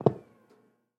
Звуки для игр
Шум полета на короткую дистанцию или вниз